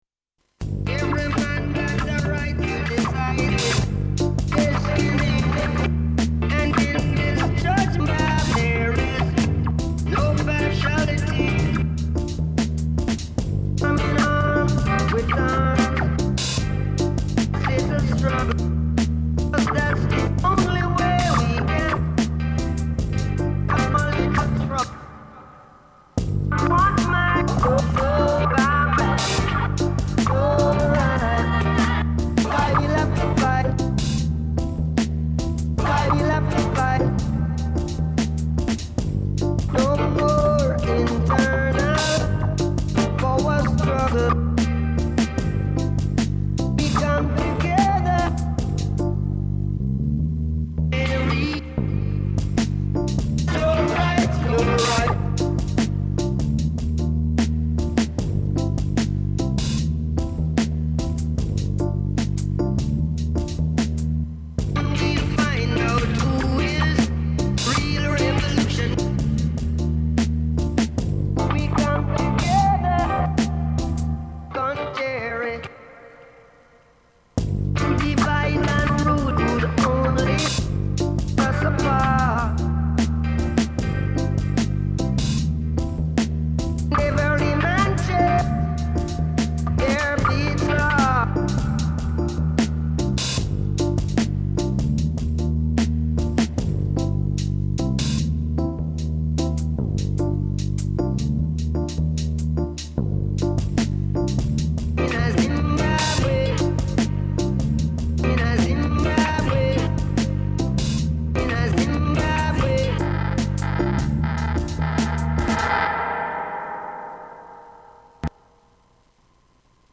BreakBeat Roots Rock Dub Remix